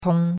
tong1.mp3